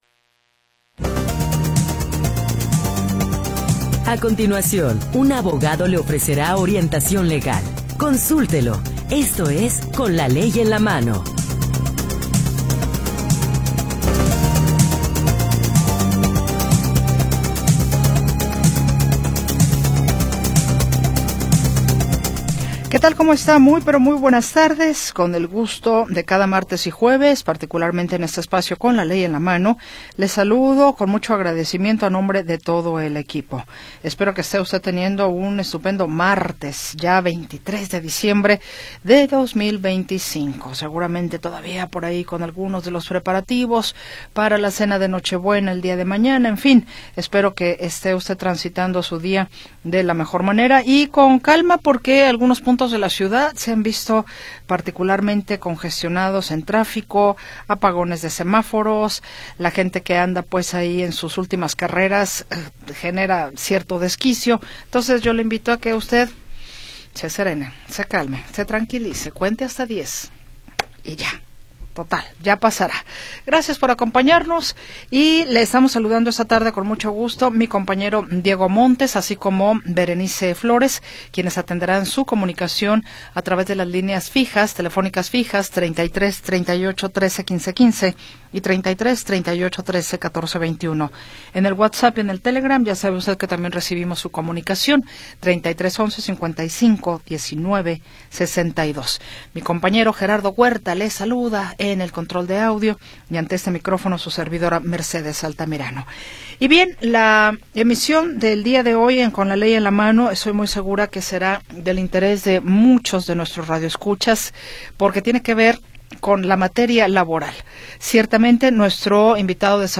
Orientación legal de jueces y abogados especialistas
Programa transmitido el 23 de Diciembre de 2025.